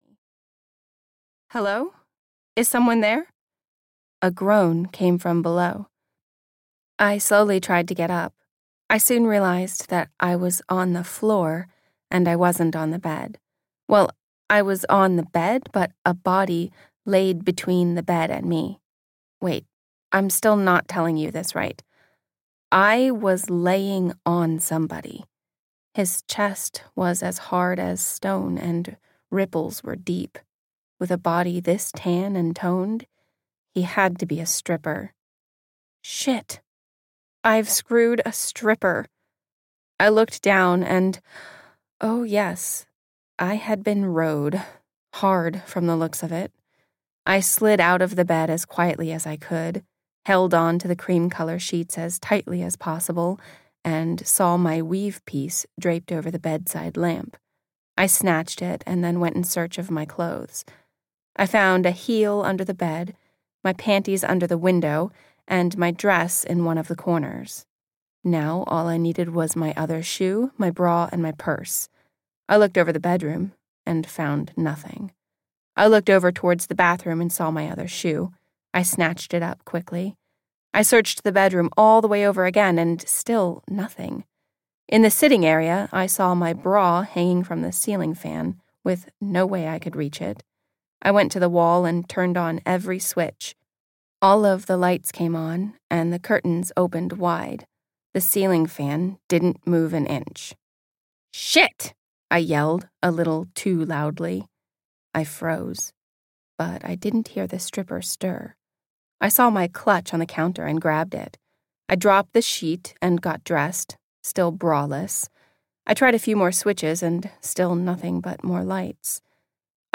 The Marriage That Didn’t Stay In Vegas (EN) audiokniha
Ukázka z knihy